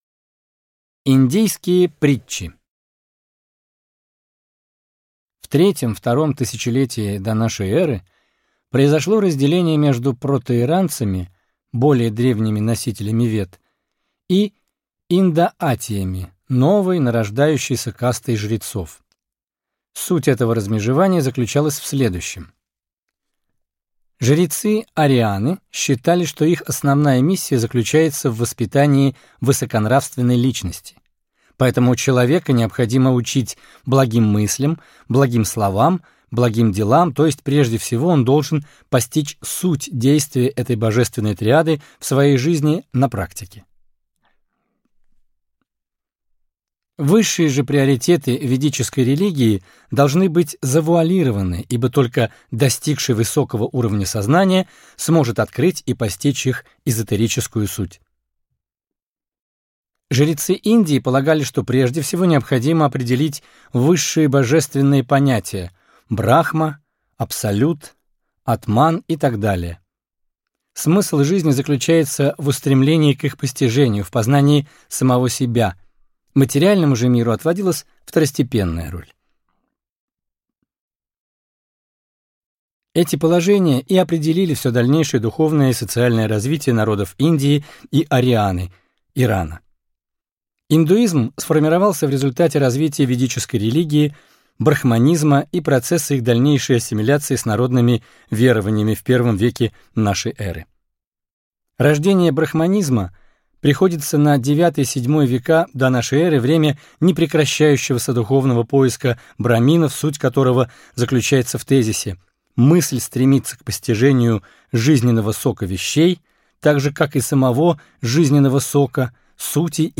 Аудиокнига Индийские притчи | Библиотека аудиокниг